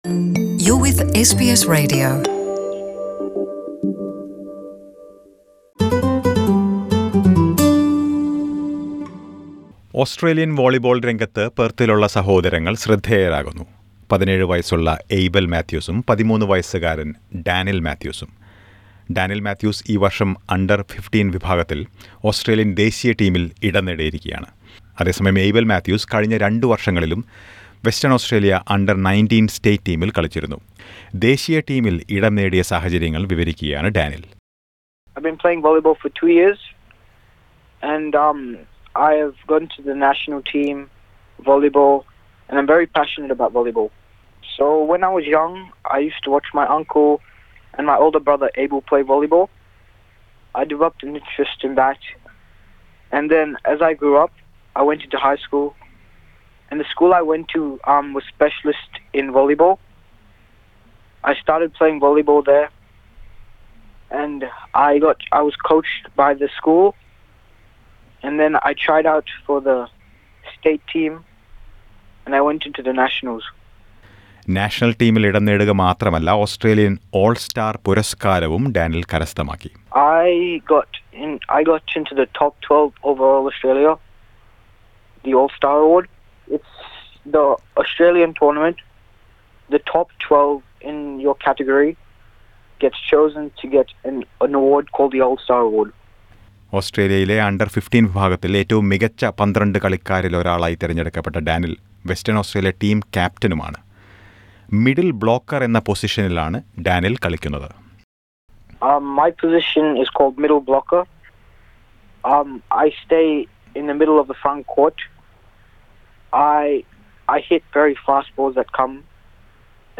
Listen to a report about their journey so far.